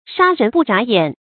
注音：ㄕㄚ ㄖㄣˊ ㄅㄨˋ ㄓㄚˇ ㄧㄢˇ
殺人不眨眼的讀法